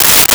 Paper Tear 05
Paper Tear 05.wav